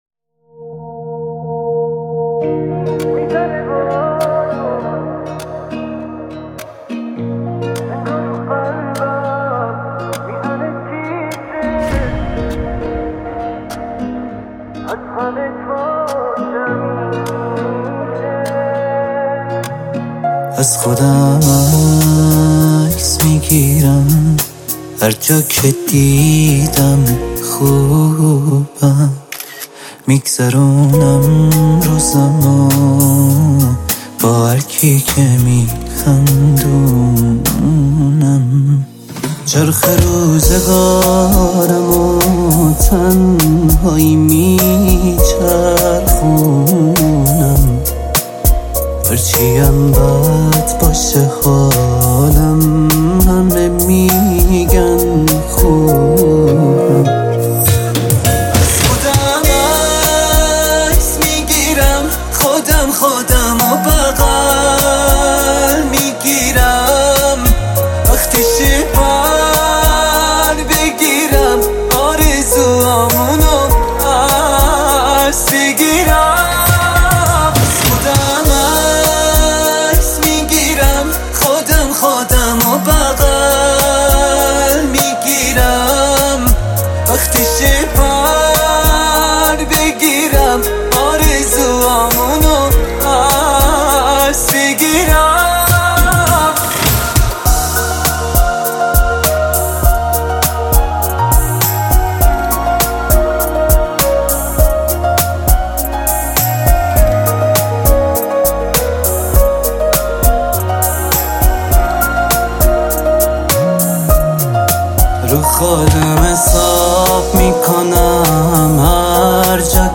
، تک آهنگ ها ، غمگین